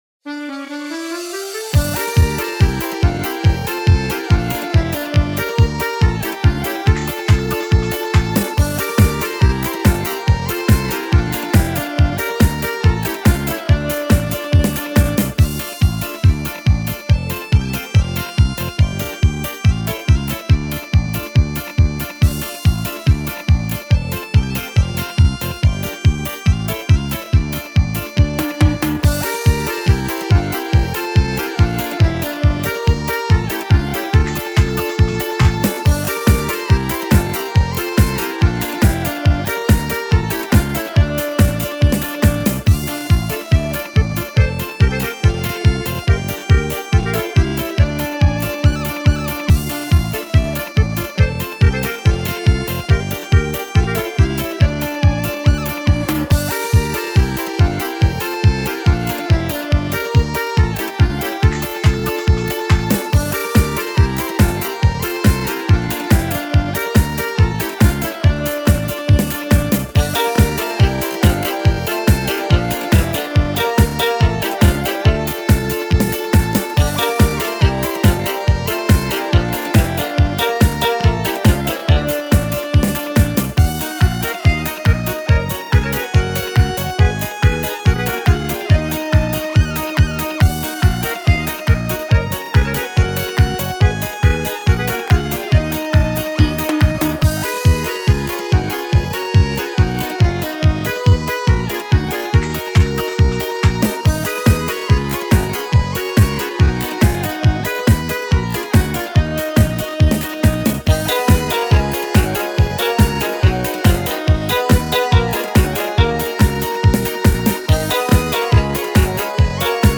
Мінусовки [5]